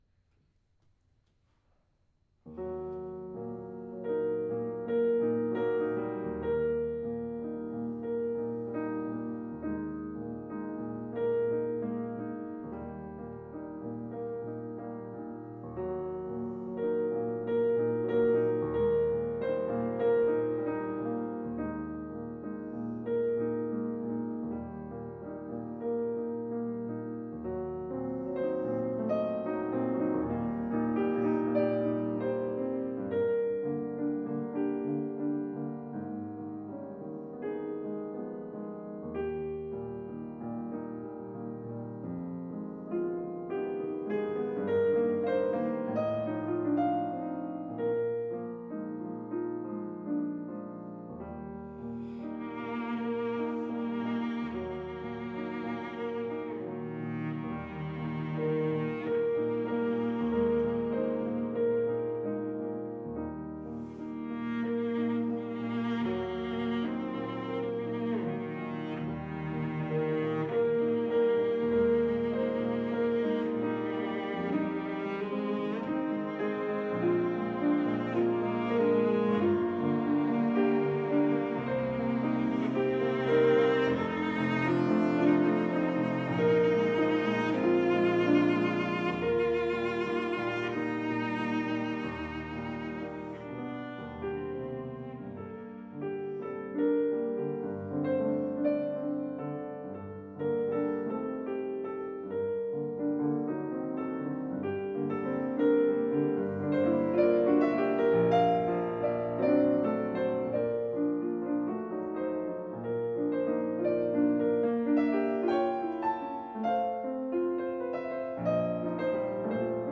Rachmaninov Cello Sonata, III